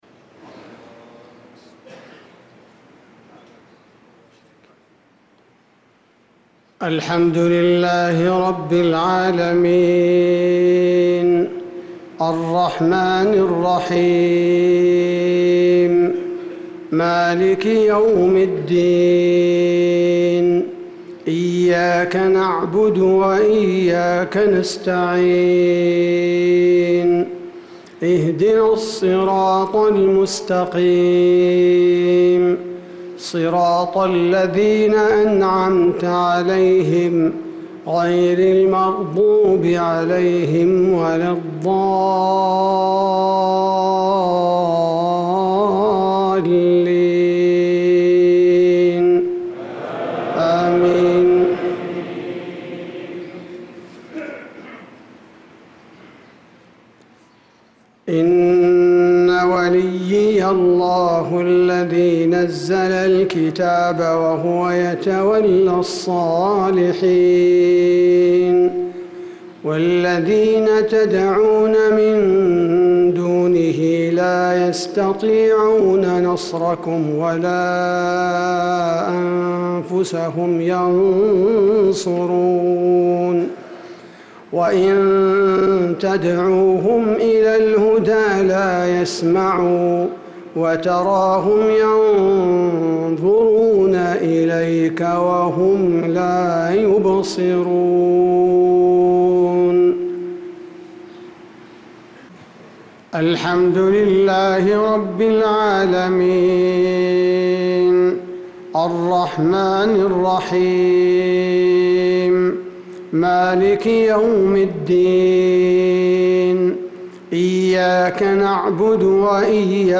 صلاة المغرب للقارئ عبدالباري الثبيتي 1 ربيع الآخر 1446 هـ
تِلَاوَات الْحَرَمَيْن .